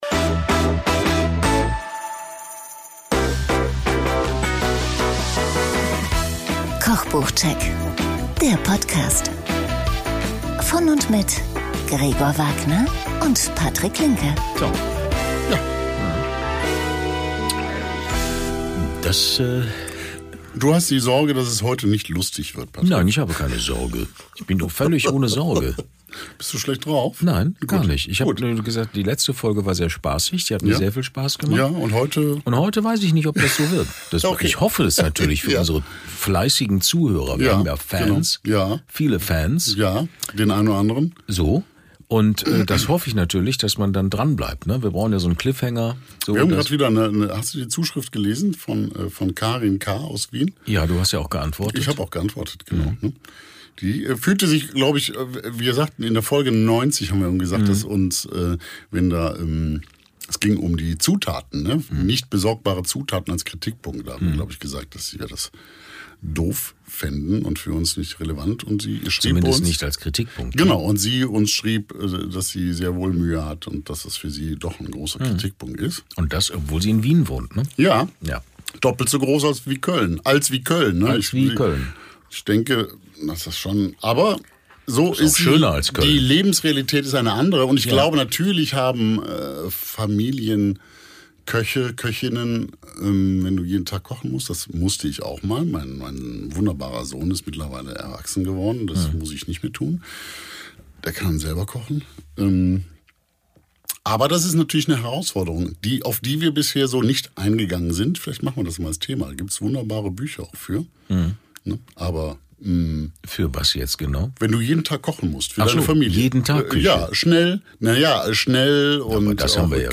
Und Tarik Rose verrät uns im anschließenden Interview warum.